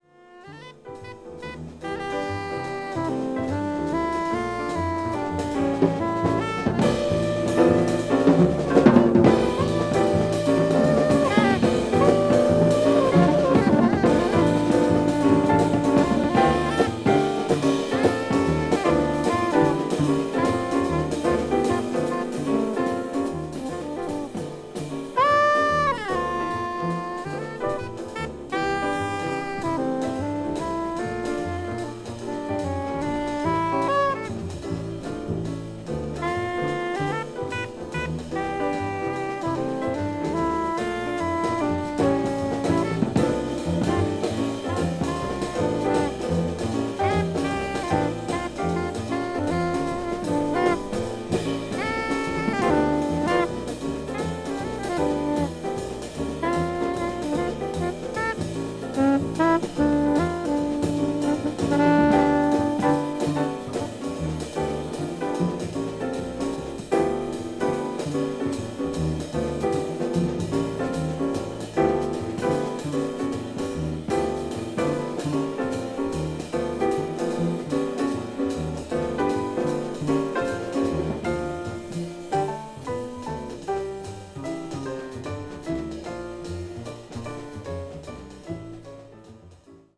media : EX/EX,EX/EX(some slightly noises.)